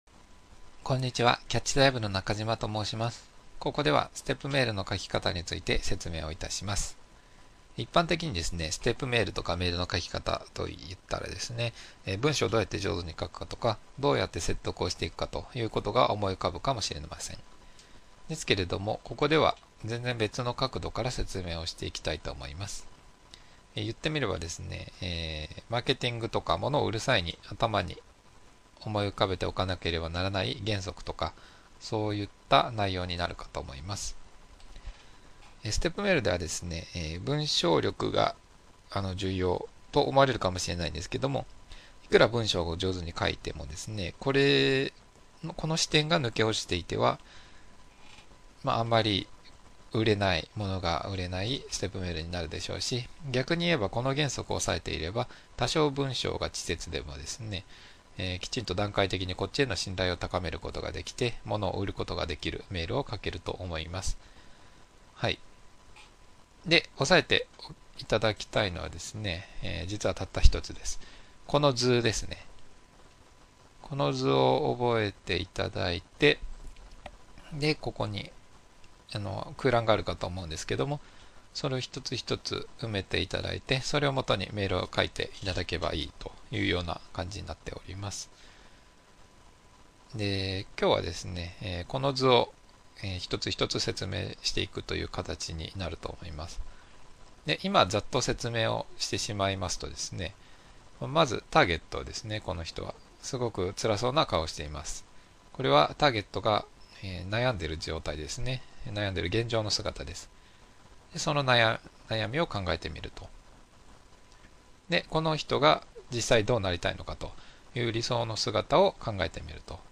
追記 音声が聞き取りにくいというお問い合わせをいただきました。 音量を大きくしたデータを作成いたしましたので、下記よりダウンロードしてください。